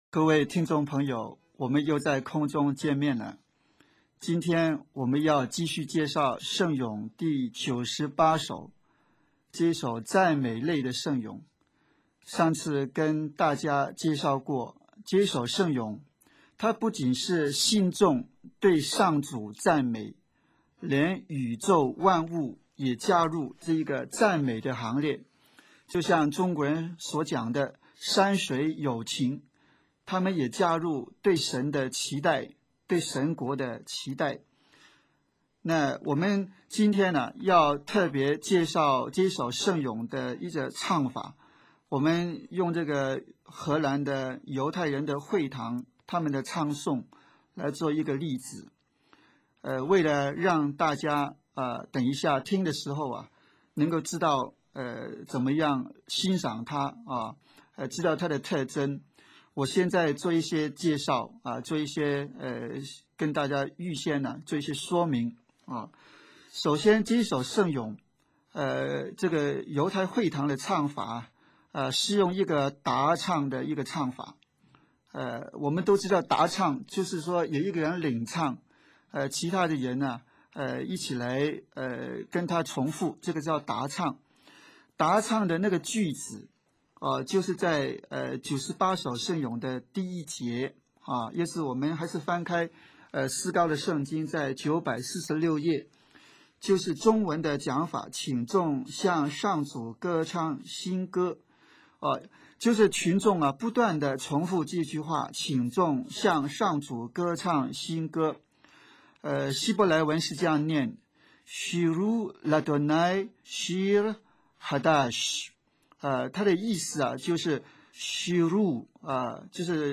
本集介绍荷兰“犹太人会堂”咏唱圣咏第九十八首的例子。其中有重覆的句子，有答唱的意味，重覆的句子就是圣咏第九十八首的“请你们向上主歌唱新歌”。